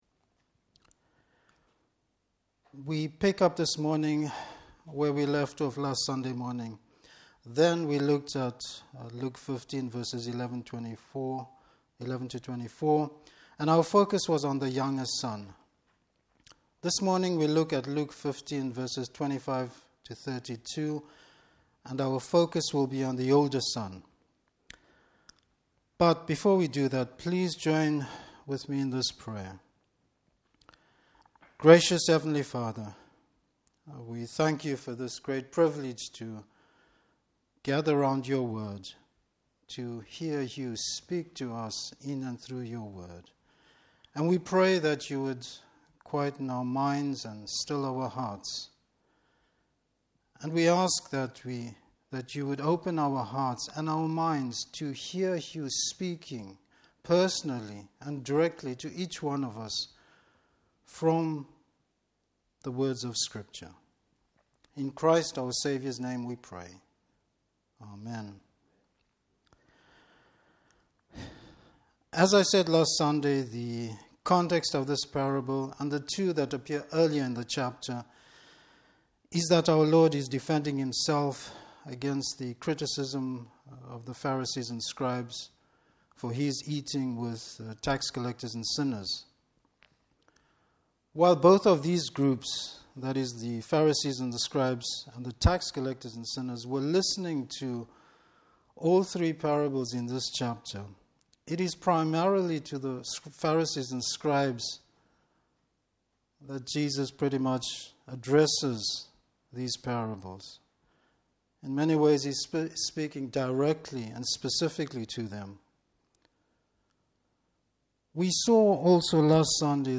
Service Type: Morning Service Bible Text: Luke 15:25-32.